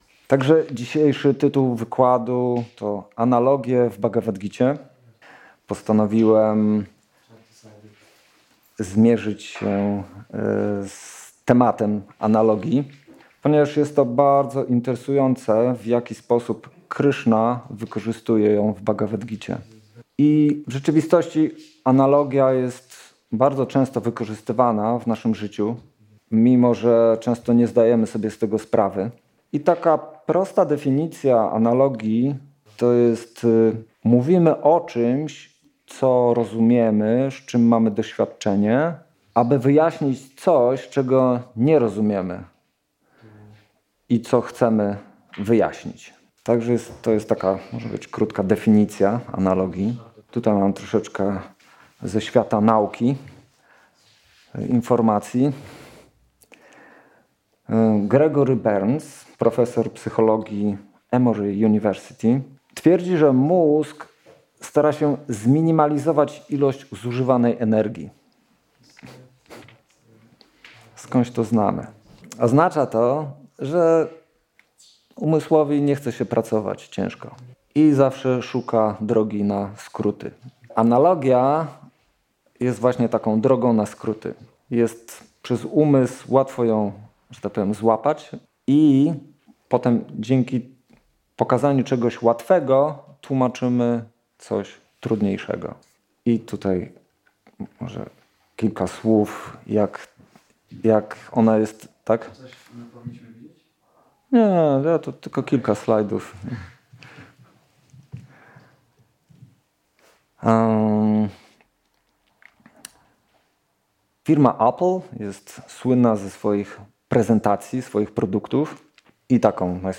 Prezentacja nagrana 12 czerwca 2022 roku.